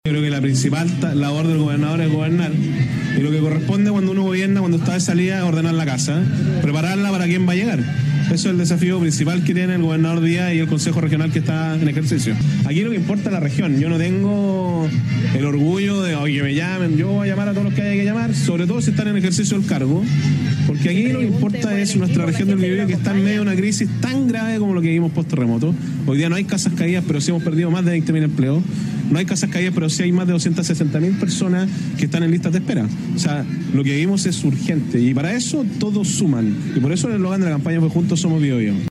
Tras conocerse los resultados, en un punto de prensa, Giacaman señaló que “lo que corresponde es preparar la casa para quien va a llegar”, en relación a las tareas de traspaso de mando de parte del actual gobernador Rodrigo Díaz.